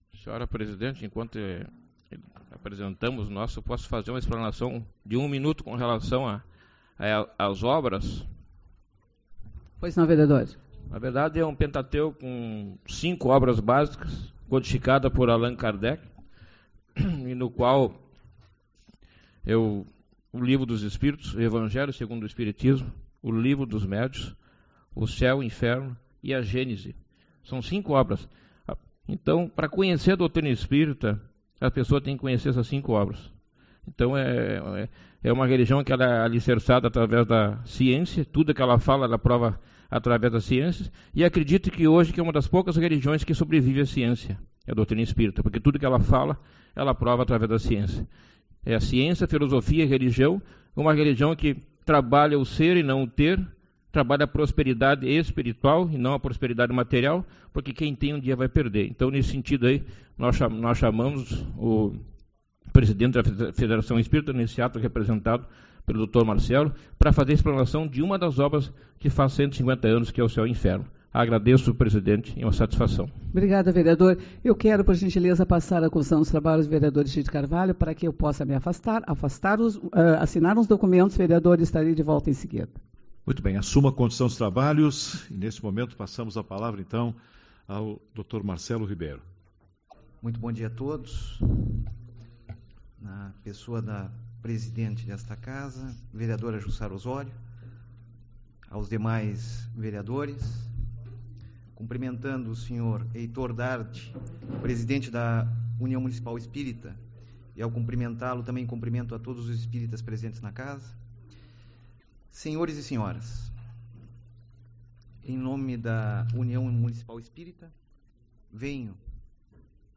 Você está aqui: Página Inicial / Comunicações / Galeria de Áudios / 2015 / Agosto / 06/08 - Reunião Ordinária / Parte 2-Trib.